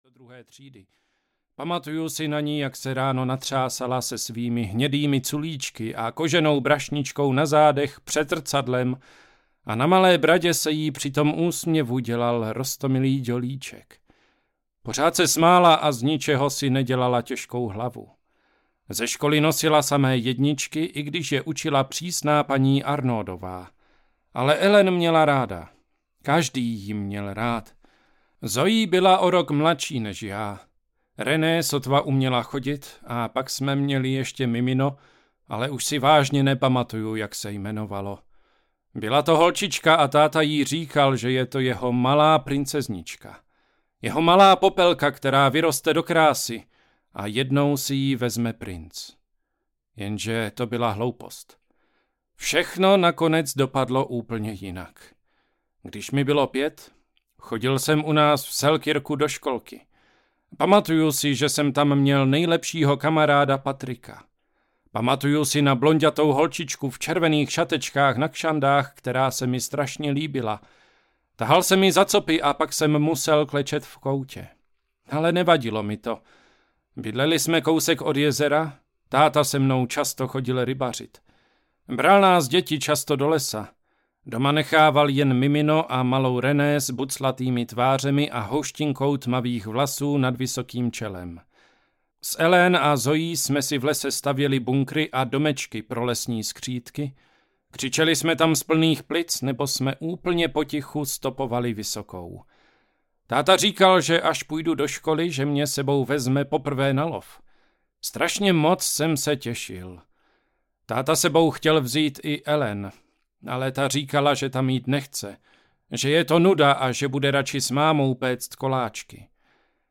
V pekle jsme všichni Hébert audiokniha
Ukázka z knihy